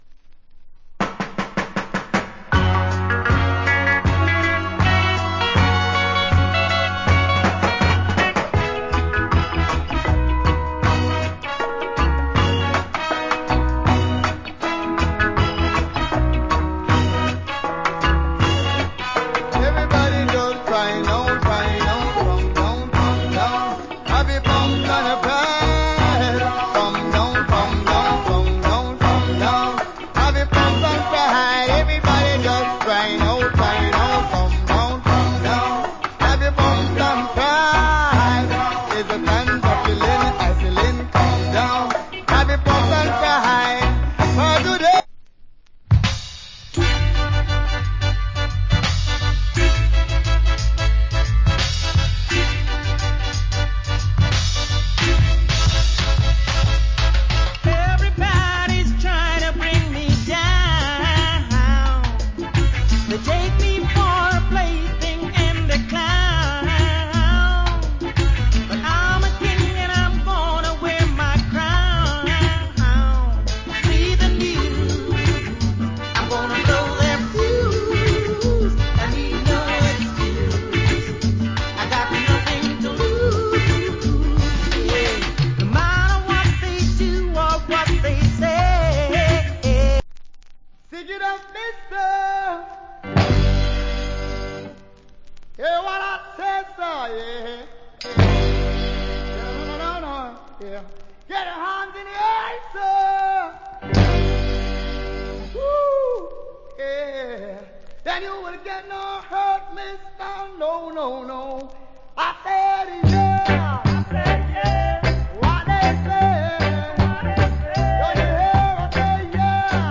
REGGAE
Early 70's Nice Reggae Compilation Album.